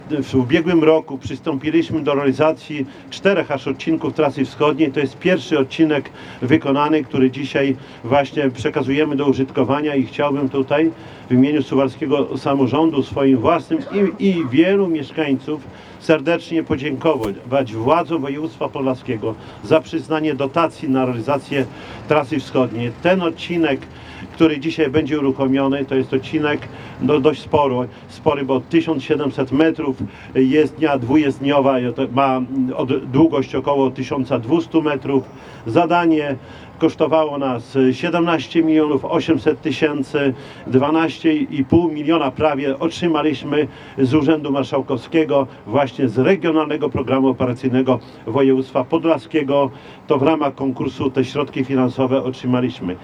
– Miasto otrzymało na ten cel dofinansowanie z Regionalnego Programu Operacyjnego Województwa Podlaskiego – mówi Czesław Renkiewicz, prezydent Suwałk.